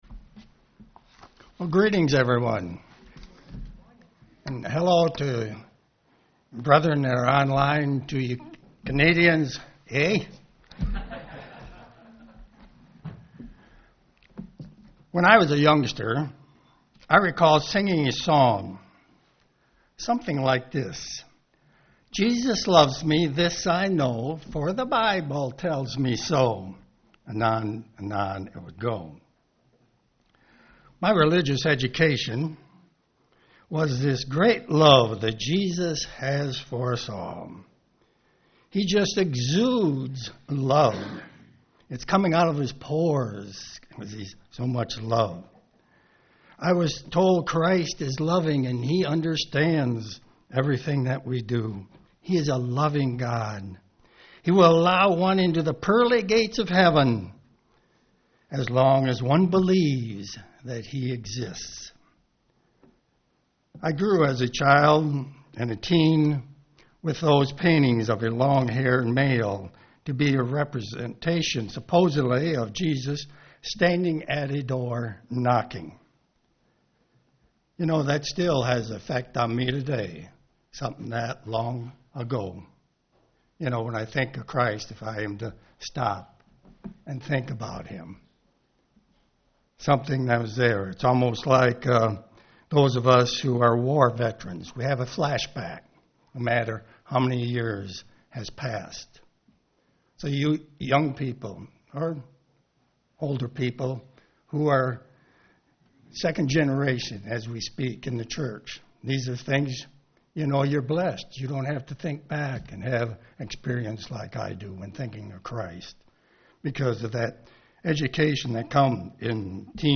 Sermons
Given in Ann Arbor, MI